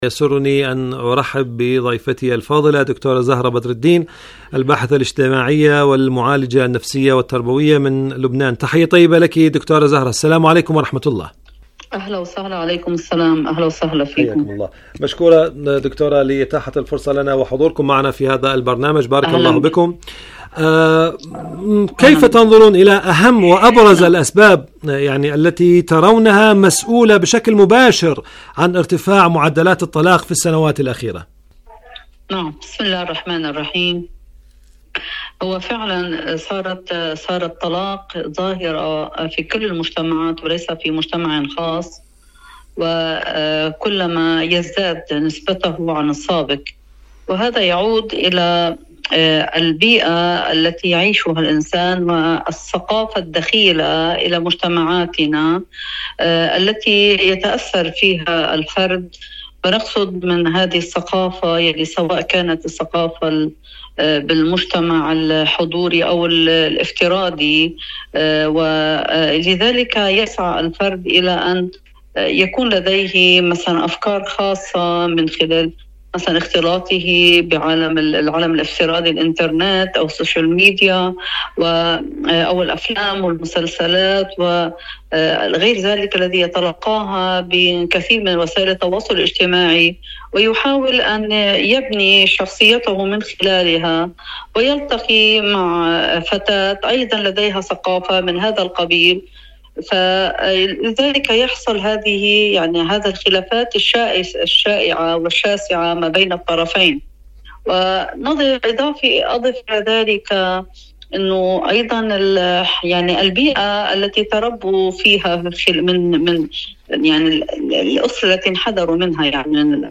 مقابلات برامج إذاعة طهران العربية برنامج معكم على الهواء مقابلات إذاعية الأسرة الإسلامية الأسرة لماذا تعجز مجتمعاتنا في تقليل نسبة الطلاق؟ مجتمعاتنا عاجزة عن الحد من ارتفاع نسبة الطلاق نسبة الطلاق تقليل نسبة الطلاق أسباب الطلاق الأسباب الأساسية لارتفاع معدلات الطلاق ارتفاع معدلات الطلاق من أسباب كثرة الطلاق في مجتمعنا؟